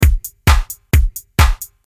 Groovy 132 BPM Drum Loop
This high-octane 132 BPM drum loop features punchy kicks, crisp snares, and driving hi-hats.
Genres: Drum Loops
Tempo: 132 bpm
Groovy-132-bpm-drum-loop.mp3